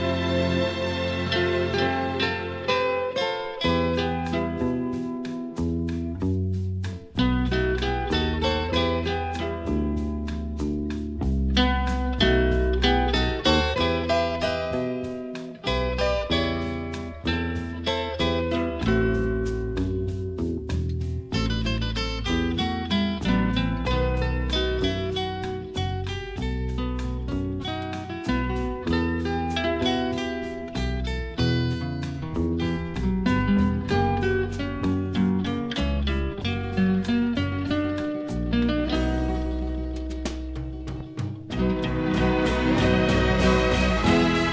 Even after meticulous restoration you still hear residual noise from a very poor source. on particularly worn albums, I'll try to catch any skips and convince the vinyl to give up an unskipped version of each song.